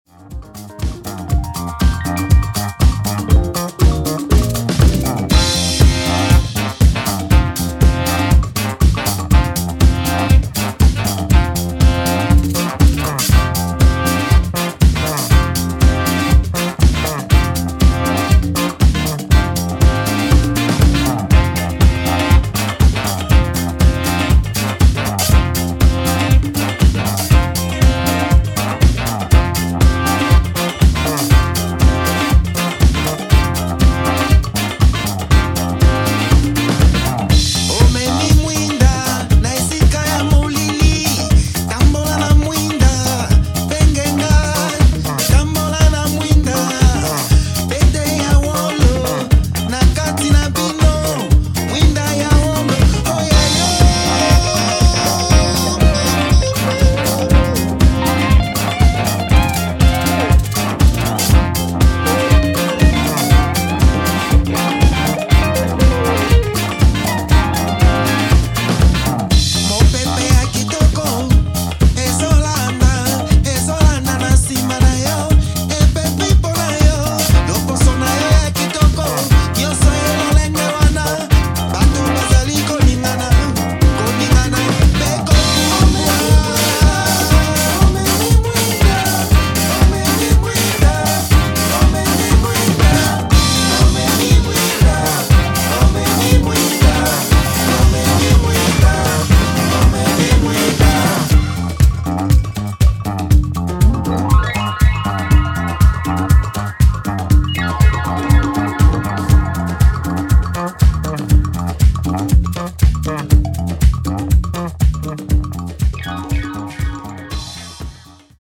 locked bass, congas, keys, percussion, rhythm guitar
Funk, disco, soul and jazz all blend for a peak time jam.